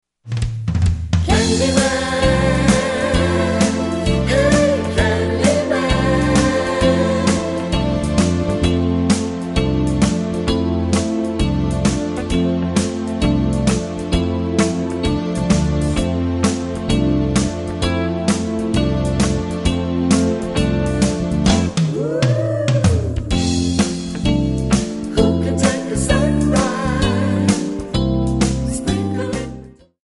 Backing track files: Musical/Film/TV (484)